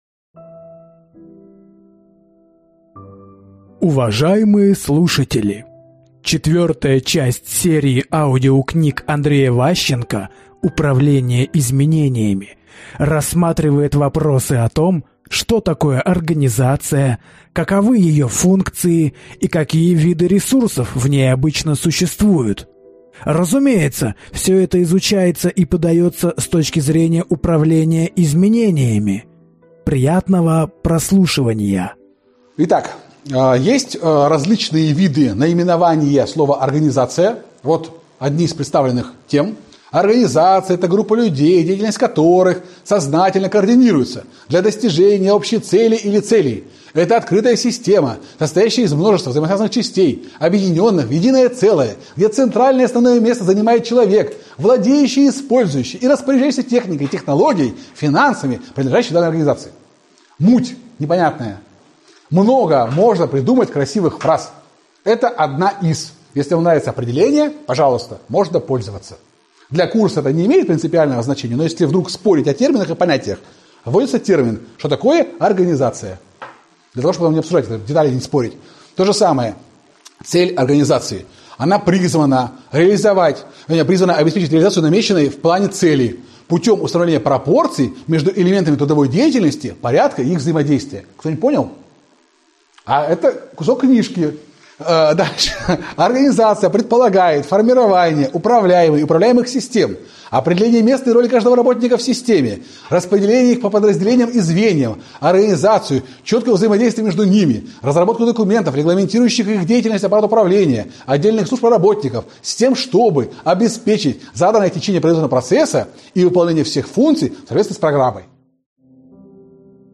Аудиокнига Управление изменениями. Российская практика. Часть 4 | Библиотека аудиокниг
Прослушать и бесплатно скачать фрагмент аудиокниги